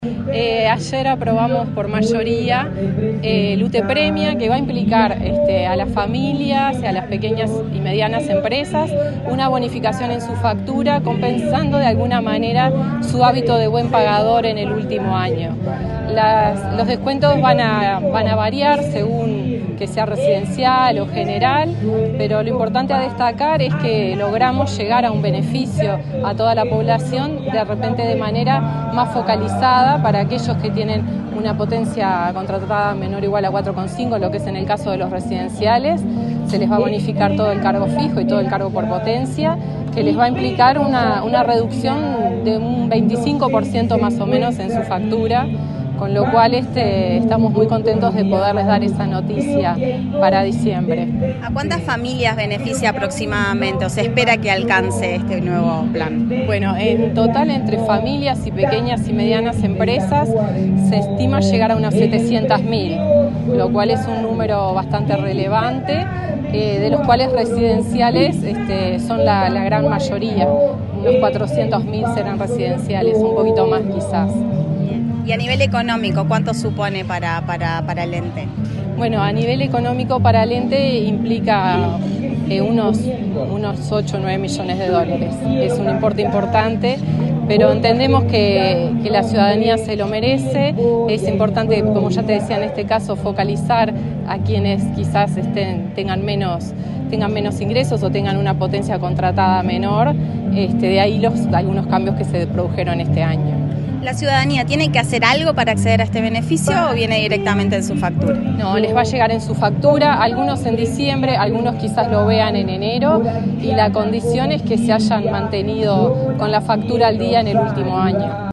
Declaraciones de la presidenta de UTE, Andrea Cabrera